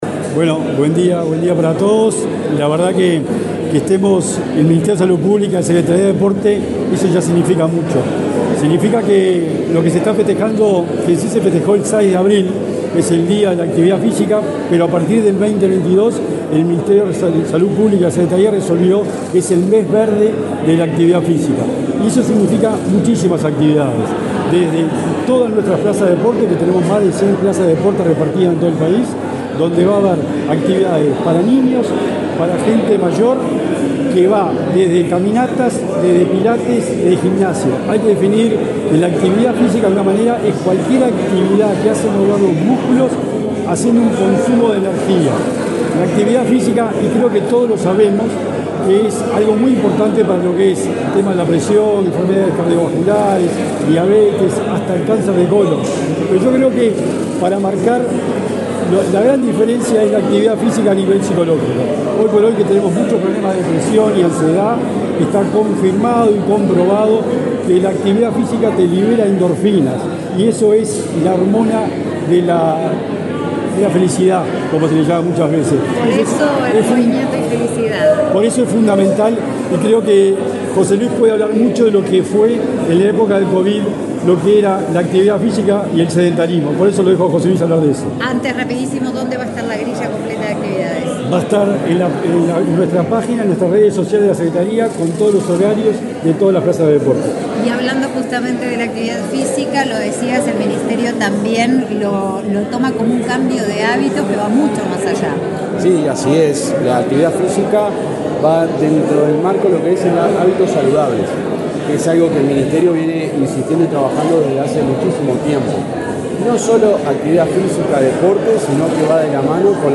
Declaraciones de autoridades en acto en la SND
El subsecretario de Salud Pública, José Luis Satdjian, y el secretario nacional del Deporte, Sebastián Bauzá, fueron entrevistados por medios